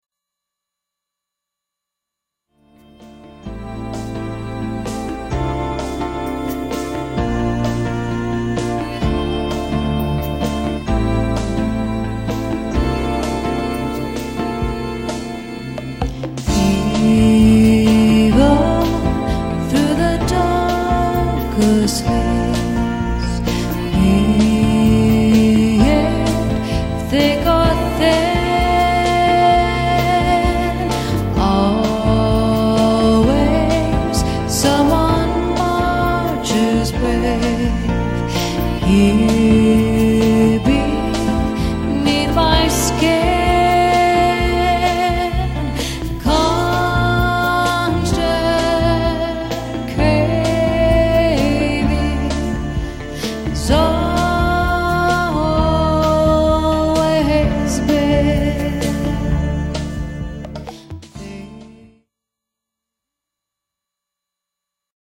musical duo featuring vocalist/keyboardist